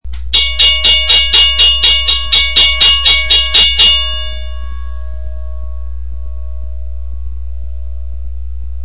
WRRS Bell #3
CLICK THE ICON TO THE LEFT TO HEAR THE BELL IN ACTION.